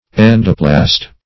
Endoplast \En"do*plast\, n.